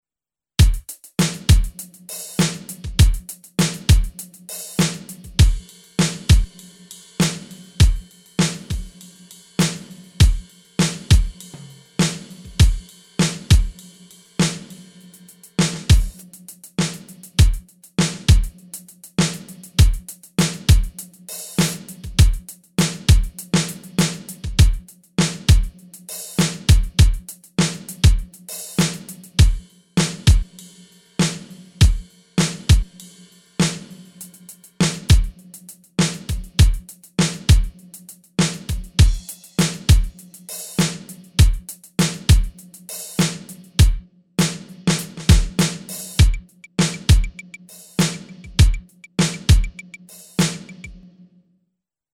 Rackmount drum brain based on PCM samples with analog triggers and basic Midi control.
Percussives sounds and drum kits are organized into 6 sounds categories: kick, cymbal, snare, tom, percussion and effects.